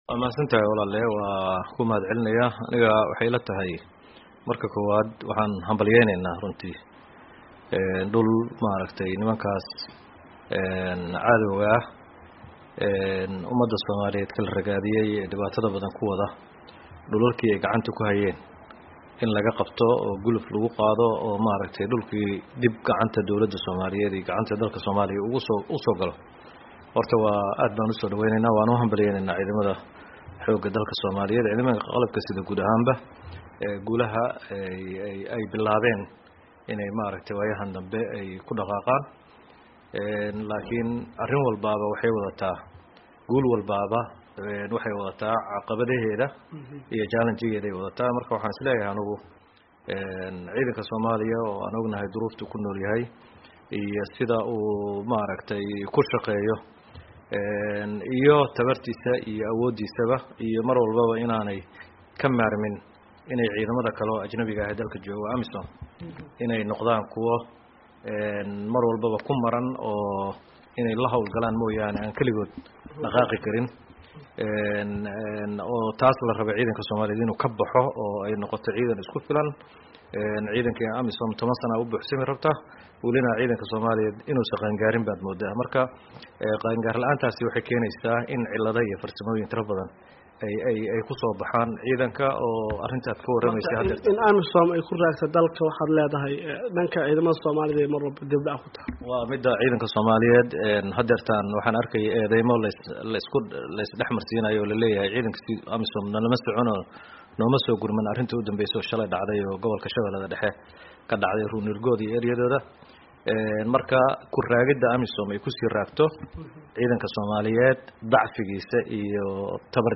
Wareysi: Axmed Macallin Fiqi